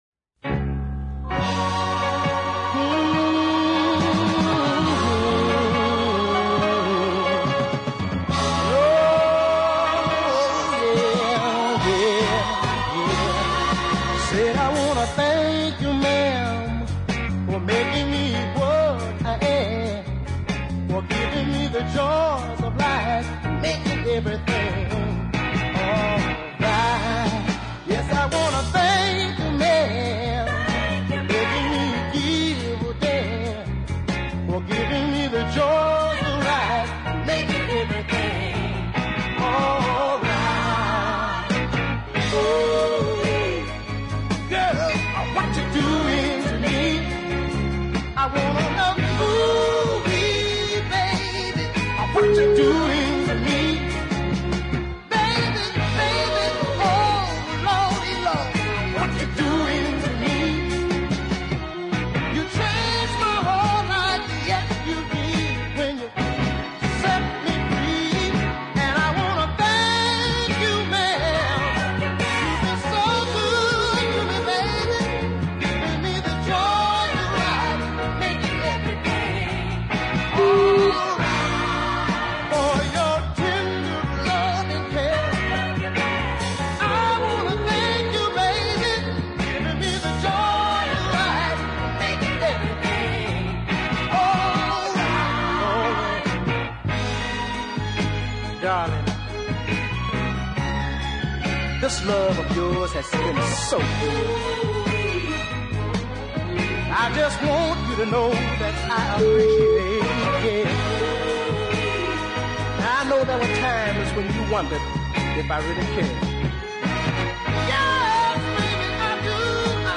soulful vocals